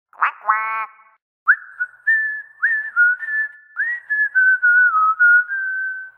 Tahací kachna, která vydává zvuky, když ji dítě táhne - kváká, pozpěvuje a píská. Zvuky se spouštějí automaticky, když je kachna v pohybu.
Yookidoo Tahací kachna - zvuky 1